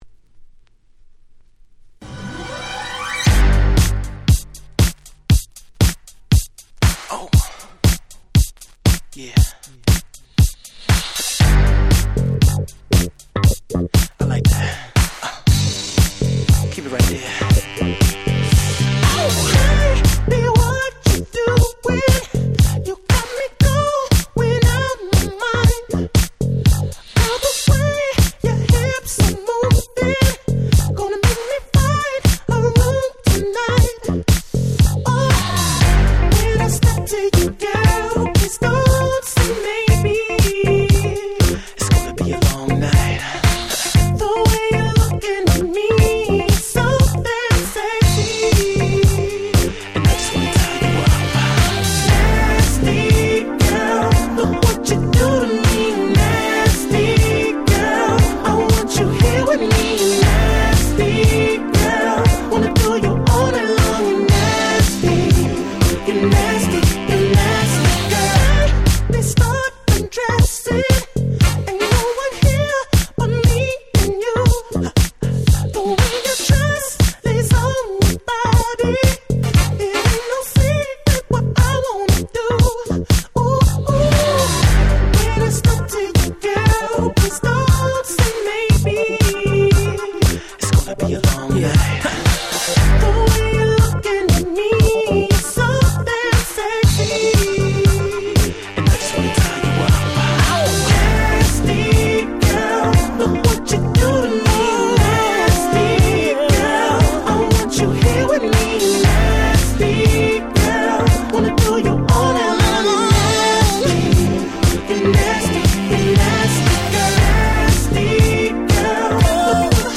07' Super Nice R&B / Neo Soul !!
軽快なDanceナンバーで超Coolです！